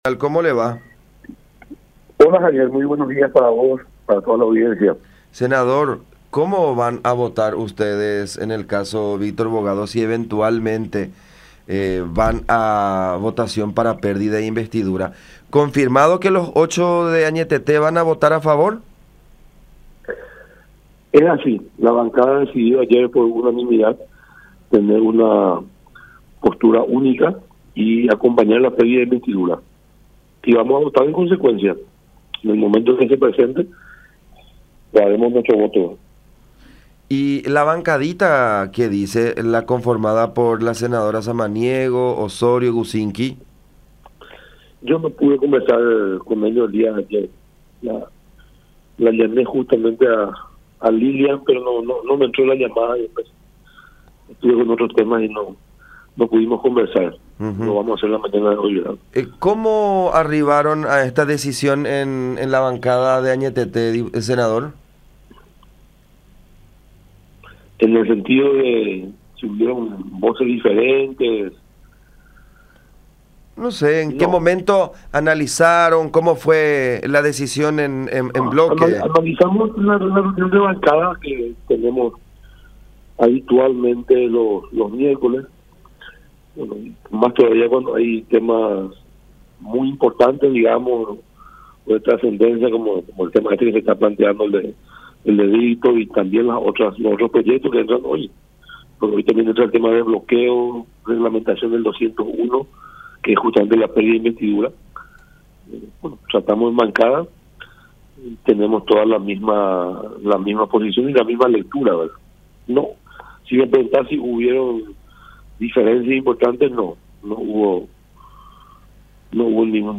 “Hemos decidido ayer que será de forma unánime, tenemos la misma visión y postura, votaremos en consecuencia”, confirmó el senador Rodolfo Friedmann, miembro de la bancada oficialista en la Cámara Alta, en conversación con La Unión. Aseveró que es un reclamo de la ciudadanía la expulsión de Bogado del Congreso.